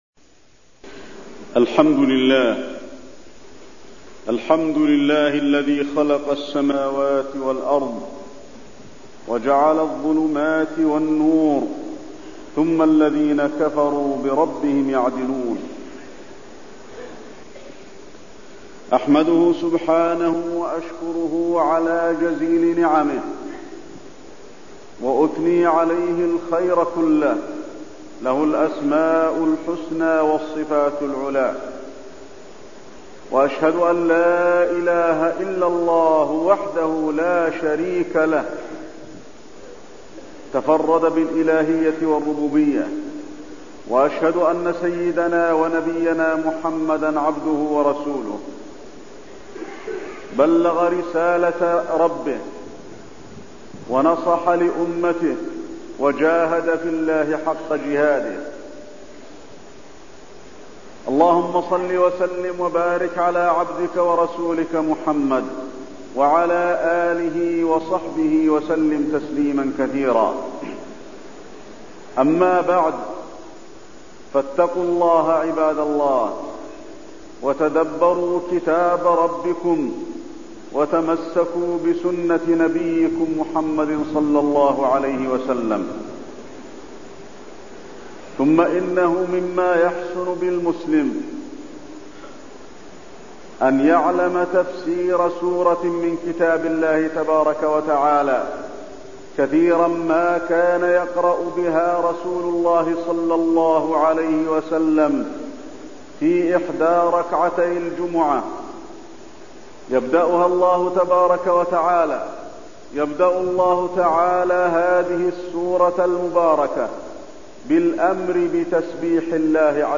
تاريخ النشر ٤ ذو القعدة ١٤٠٦ هـ المكان: المسجد النبوي الشيخ: فضيلة الشيخ د. علي بن عبدالرحمن الحذيفي فضيلة الشيخ د. علي بن عبدالرحمن الحذيفي تفسير سورة الأعلى